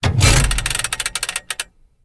controlstick2.ogg